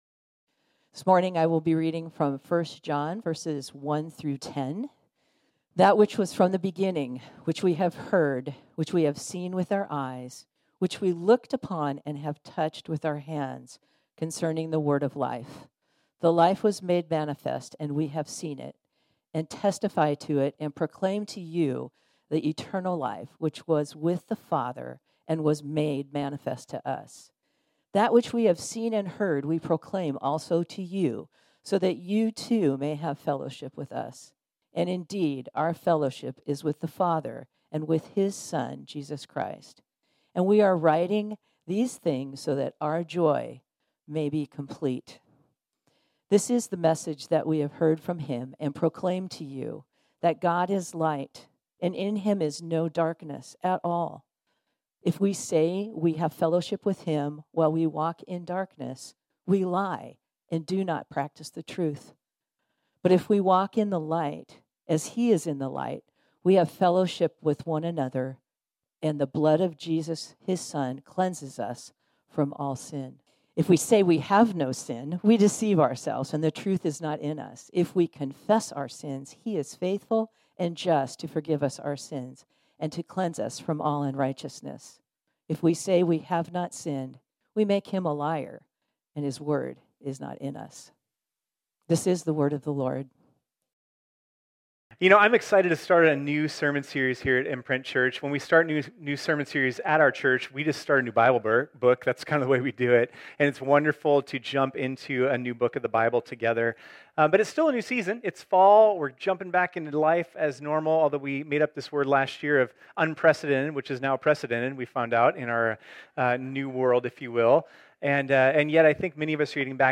This sermon was originally preached on Sunday, September 12, 2021.